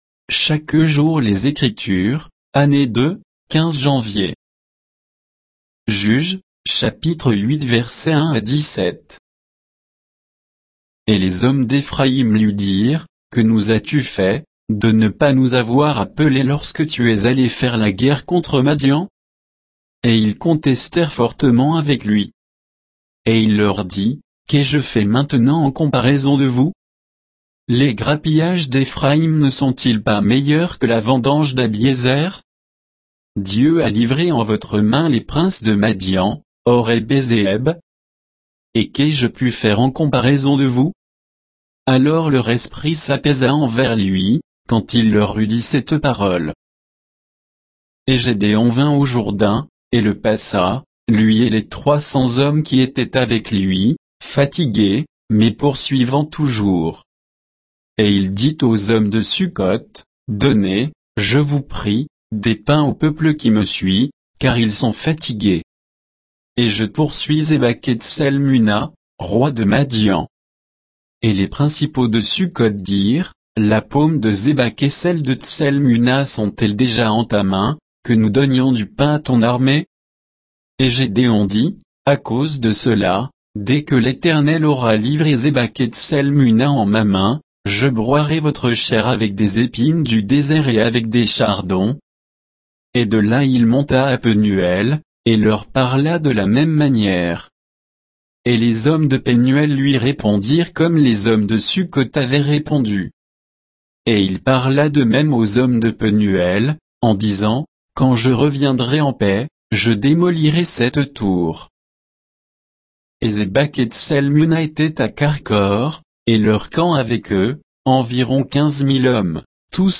Méditation quoditienne de Chaque jour les Écritures sur Juges 8, 1 à 17